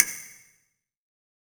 HCASTANET.wav